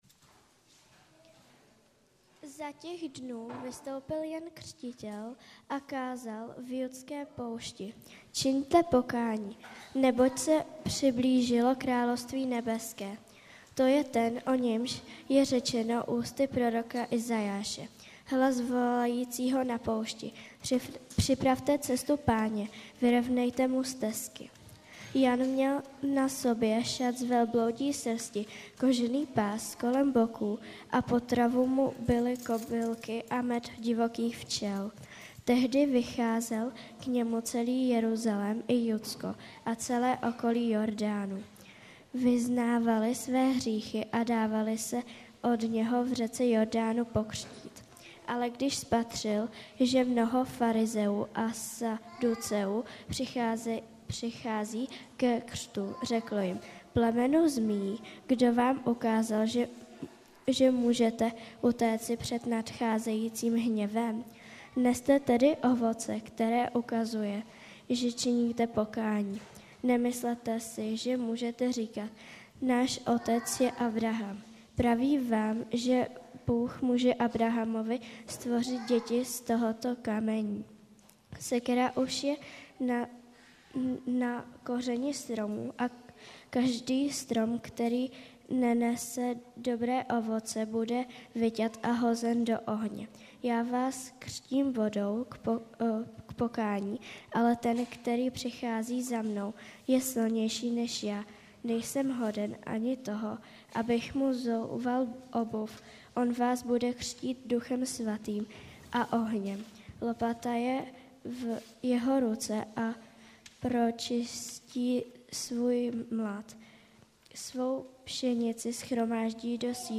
Kázání a vyučování